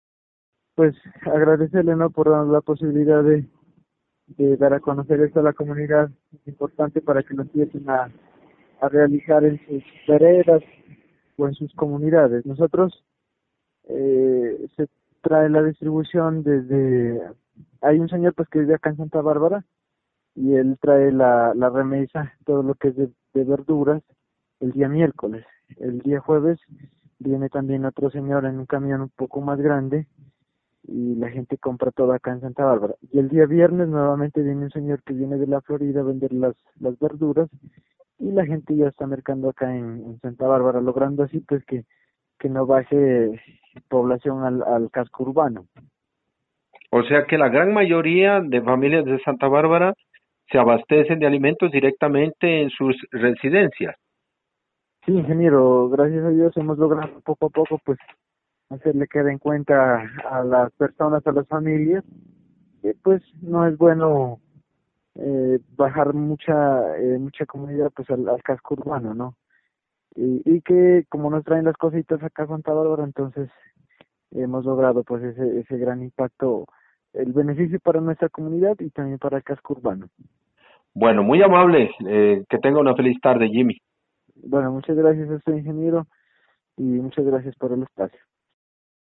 A los líderes comunitarios les hicimos la misma pregunta: ¿En la actualidad en donde se abastecen de alimentos las familias de su corregimiento?